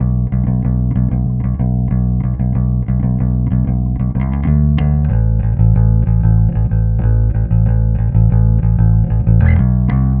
Trem Trance Bass 03c.wav